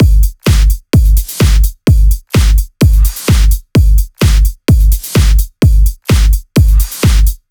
VFH2 128BPM Tron Quarter Kit 2.wav